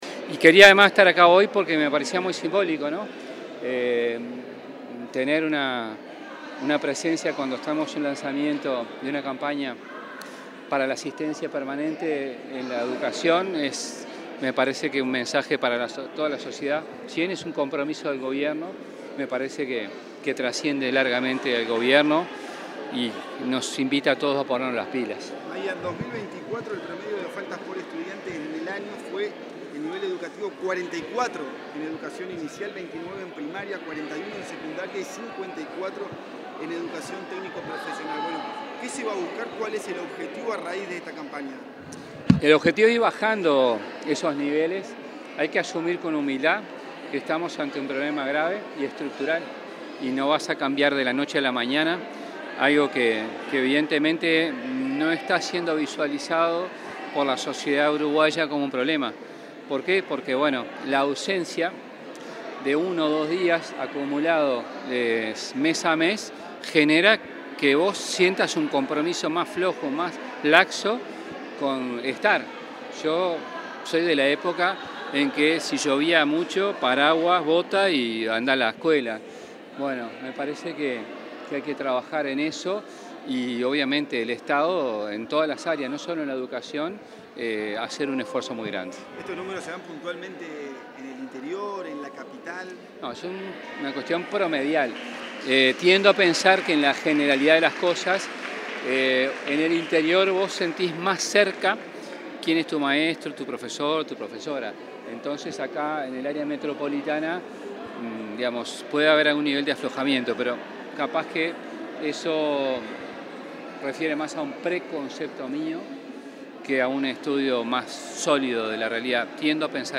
Declaraciones del ministro de Educación y Cultura, José Carlos Mahía
Declaraciones del ministro de Educación y Cultura, José Carlos Mahía 29/09/2025 Compartir Facebook X Copiar enlace WhatsApp LinkedIn Con motivo de la presentación de una campaña informativa y de concientización sobre la importancia de que los estudiantes asistan de forma regular a clases, el ministro de Educación y Cultura, José Carlos Mahía, diálogo con la prensa.